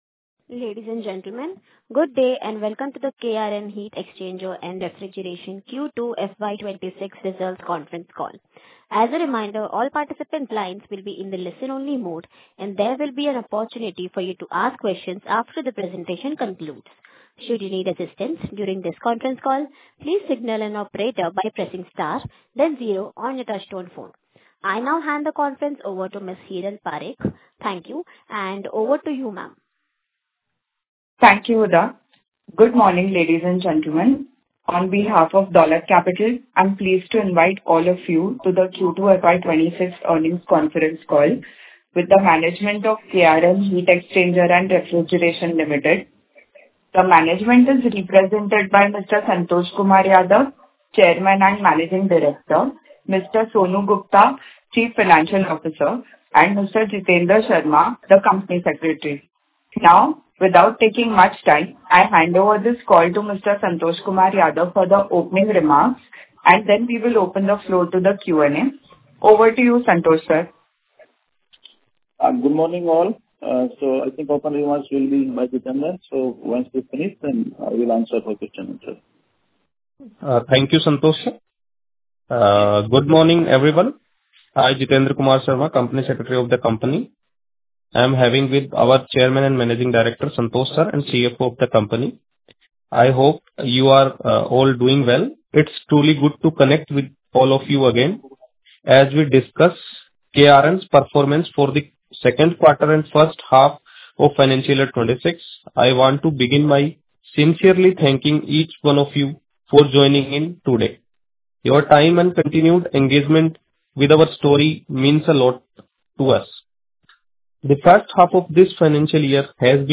Concalls
Q2-FY26-Concall-Audio-KRN-Heat-Exchanger-and-Refrigeration-Limited.mp3